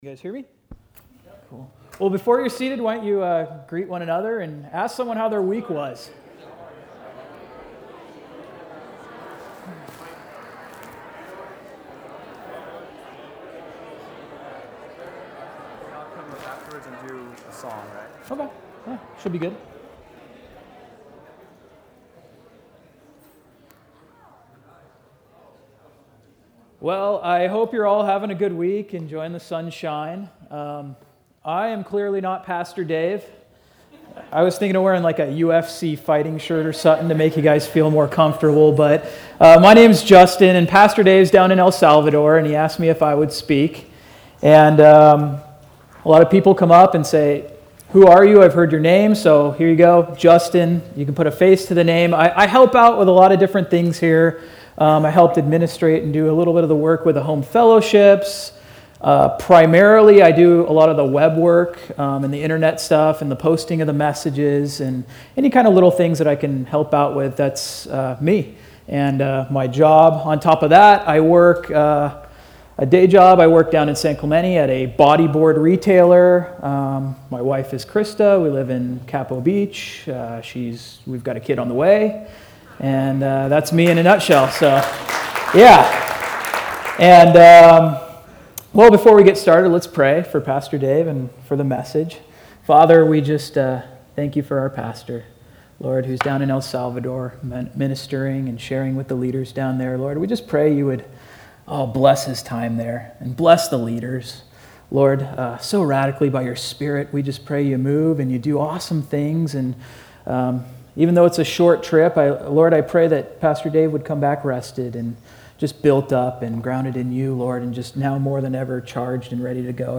A message from the series "Miscellaneous Messages."